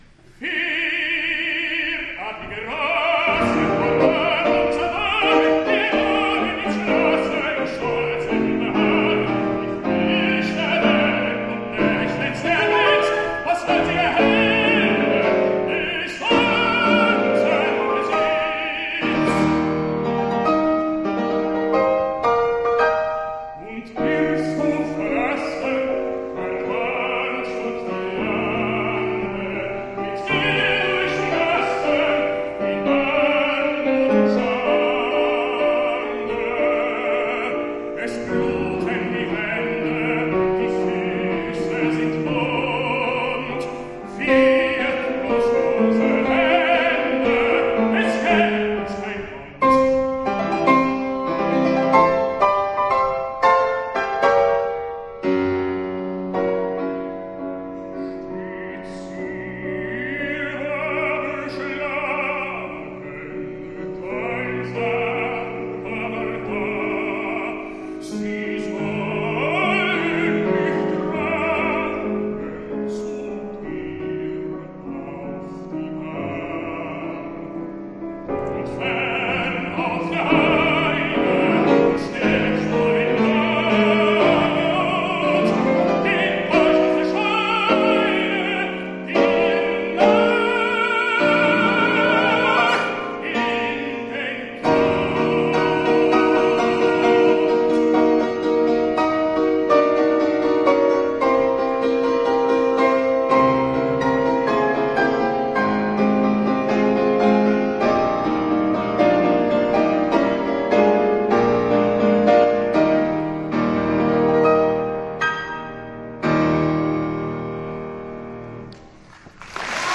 Liederabend, München, Prinzregententheater, 22. Juli 2008
Sein baritonaler, hauchig-verschatteter Tenor erwies sich diesmal auch im Leisen sehr tragfähig.
In Helmut Deutsch hatte Kaufmann einen perfekten, gut zuhörenden Begleiter, der mit hingetupften Tönen Stimmung zaubern kann.